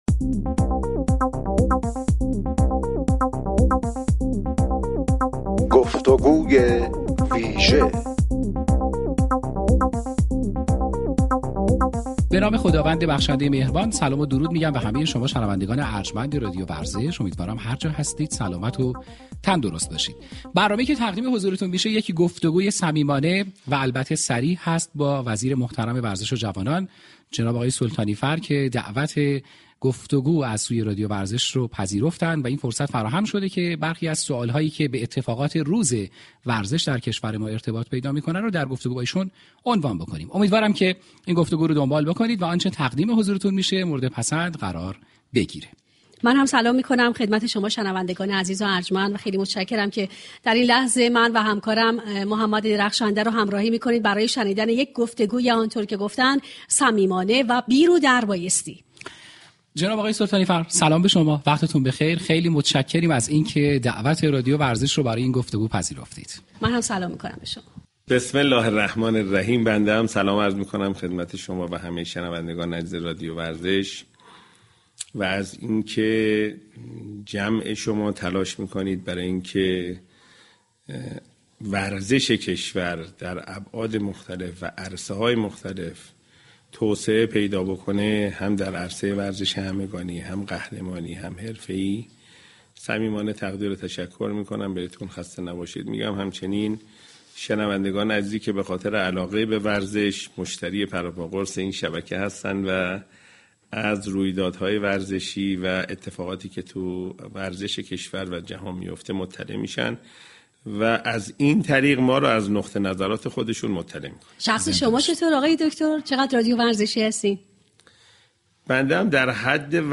وزیر ورزش و شبكه رادیویی ورزش در گفت وگویی ساده و صمیمی